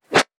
weapon_bullet_flyby_16.wav